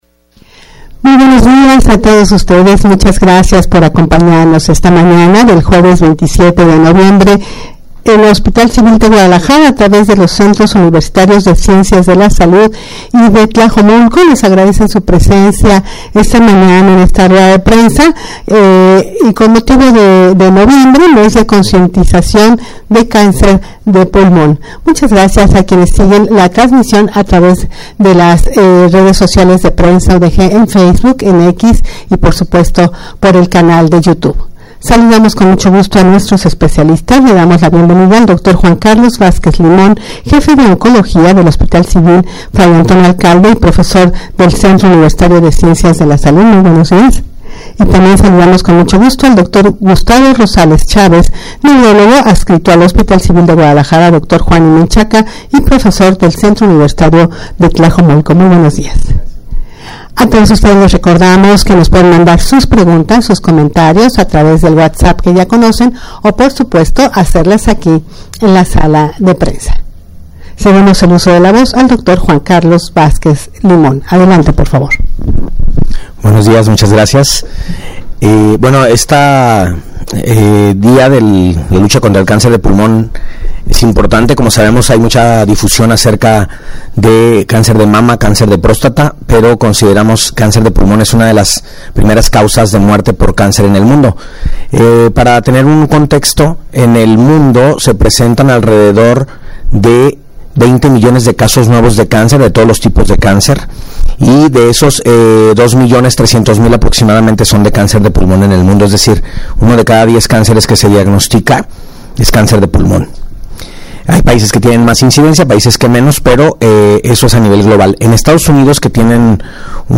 Audio de la Rueda de Prensa
rueda-de-prensa-noviembre-mes-de-concientizacion-de-cancer-de-pulmon.mp3